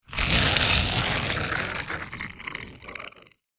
怪物之声 " 食人魔G 1
描述：像野兽一样的食人魔
标签： 生物 恐怖 动物 处理 爬行 噪声 怪物 咆哮 可怕 咆哮
声道立体声